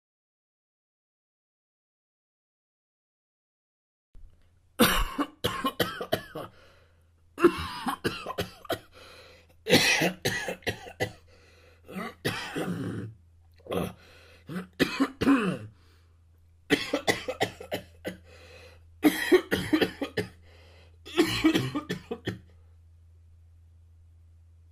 دانلود صدای سرفه – مرد،زن و بچه 2 از ساعد نیوز با لینک مستقیم و کیفیت بالا
جلوه های صوتی